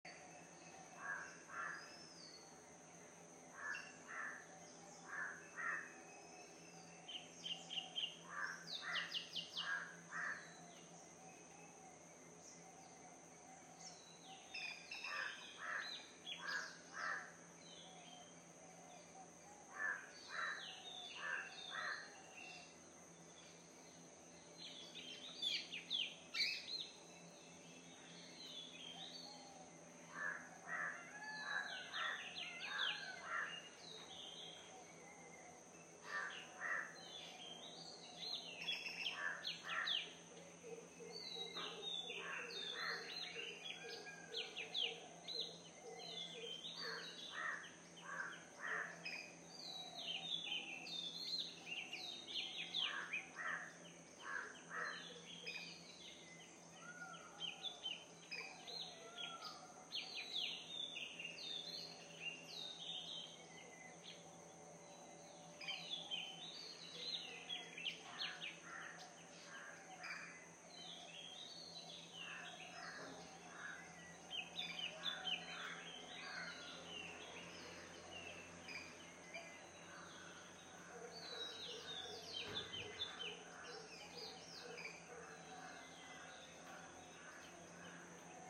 sri_lanka_nature_sound.mp3